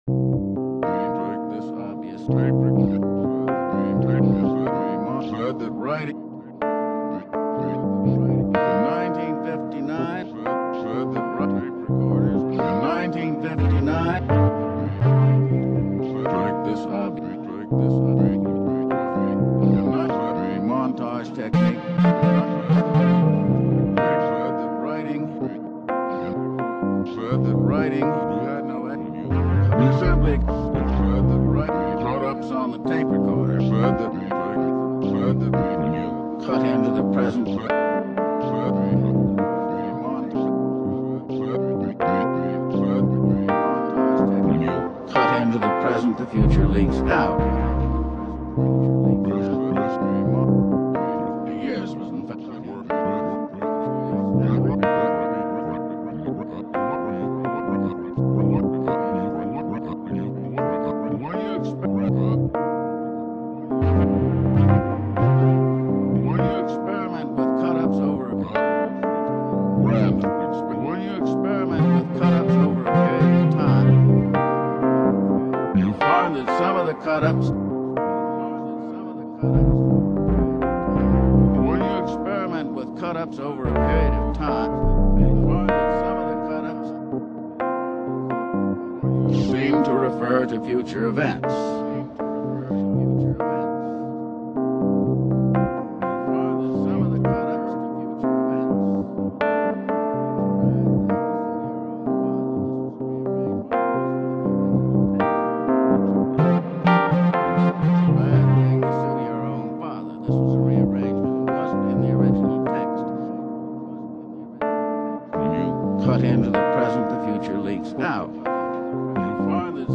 Downloaded audio from YouTube video with William Burroughs where he talks about cut up technique.
Ableton set - laid out Burrough's samples in Simpler Mix YouTube video with William Burroughs talking about cut up technique in literature Calling All Active Agents remixed video with my audio